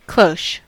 Ääntäminen
Synonyymit cloche hat dish-cover Ääntäminen : IPA : [ˈkloʊʃ] US : IPA : [ˈkloʊʃ] Tuntematon aksentti: IPA : /klɒʃ/ IPA : /ˈkl[ə/o]ʊʃ/ Haettu sana löytyi näillä lähdekielillä: englanti Käännös Substantiivit 1.